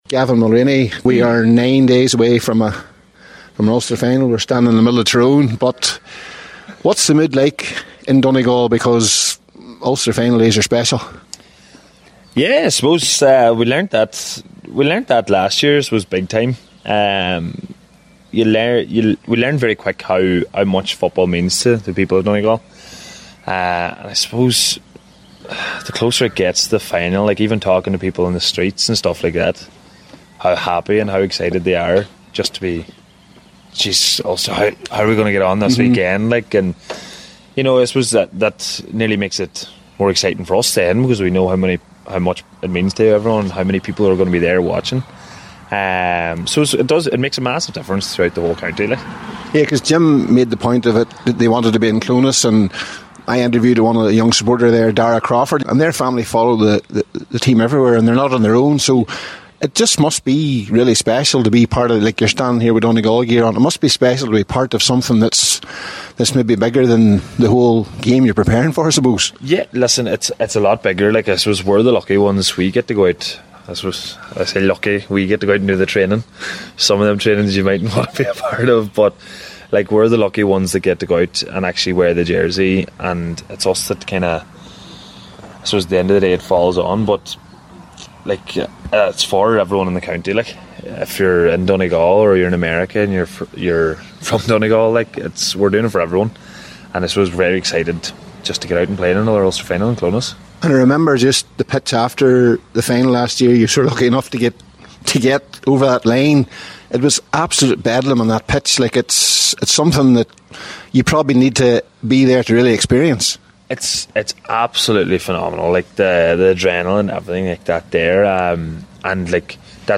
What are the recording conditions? at a press event leading up to the weekend’s game in Clones…